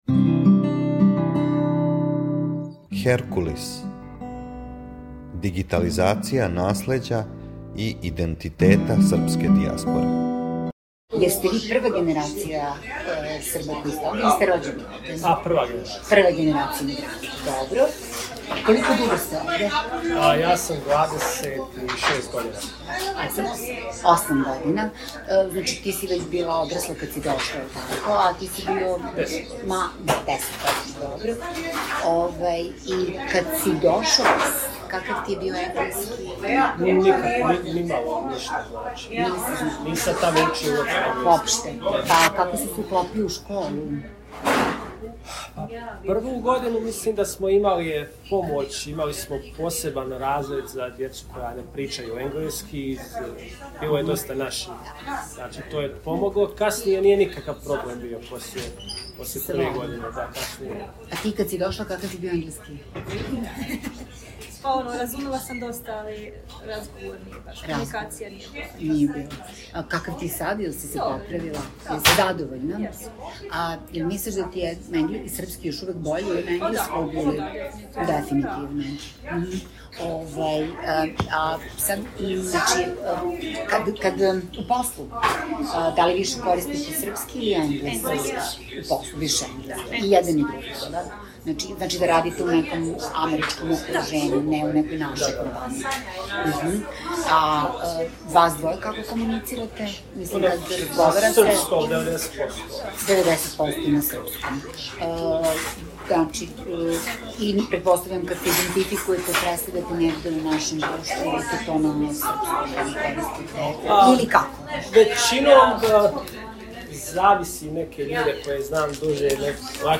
мушки
женски
интервју реализован током забаве, у башти породичне куће.